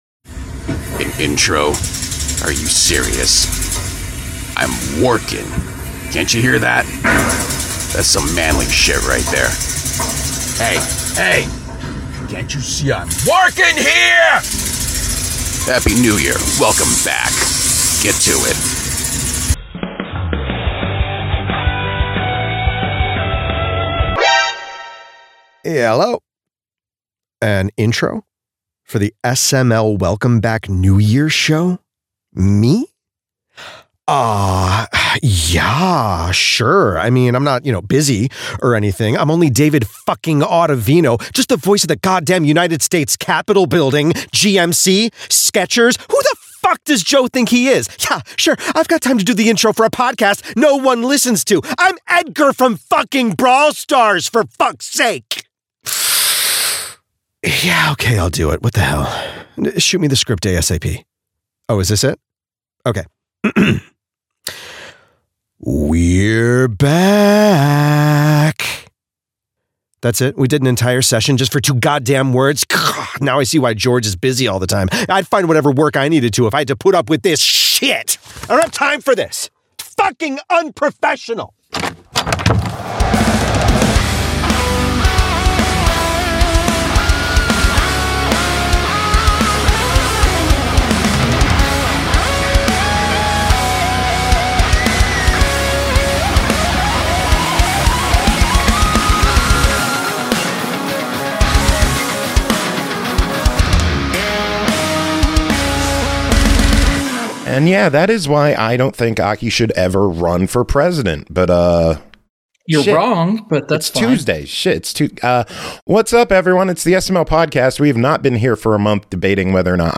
0:00 – Intro/Banter 17:53